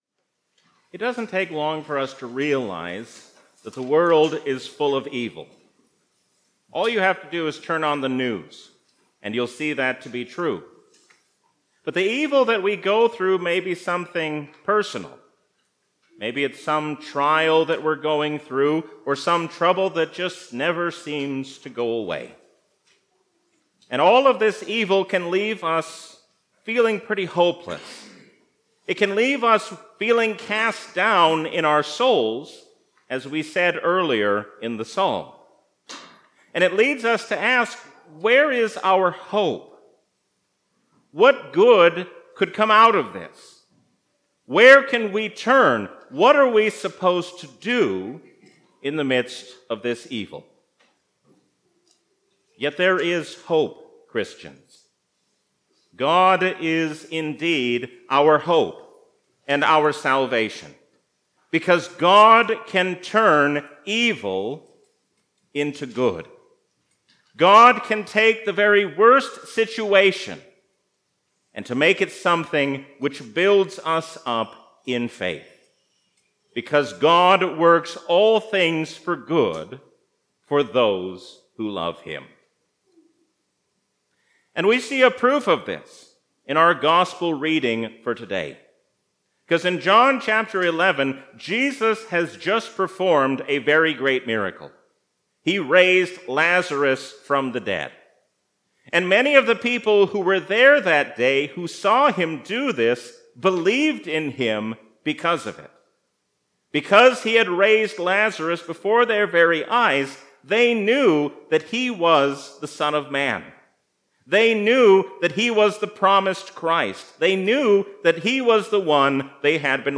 A sermon from the season "Lent 2023." Jesus teaches us what it means to seek after the will of God, even as we pray for things to be taken away.